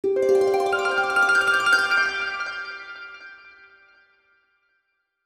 Magical Harp (10).wav